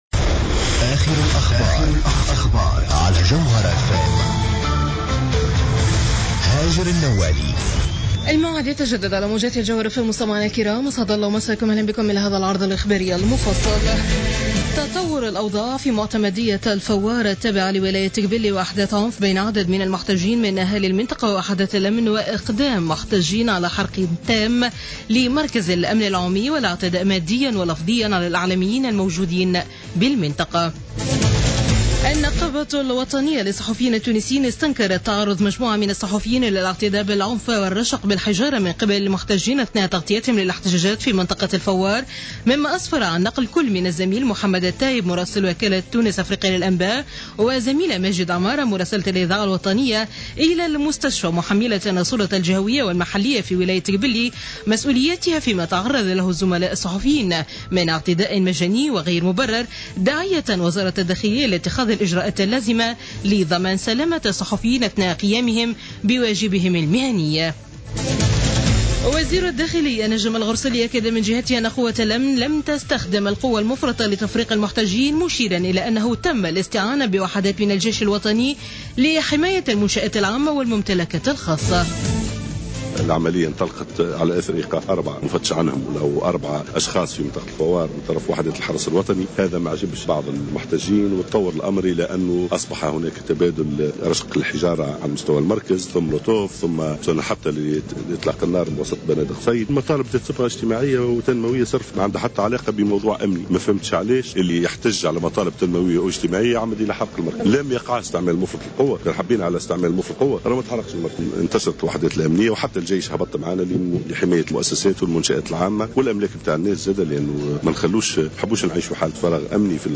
نشرة أخبار منتصف الليل ليوم الأحد 10 ماي 2015